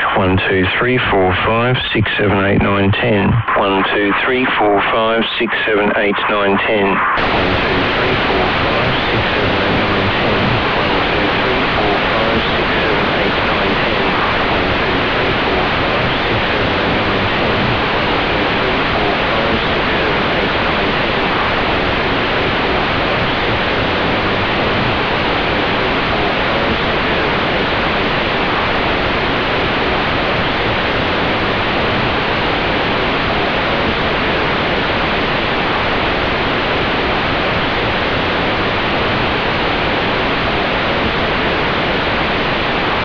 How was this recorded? Bass/NoBass test - Clean signal, maximum clipping and EQ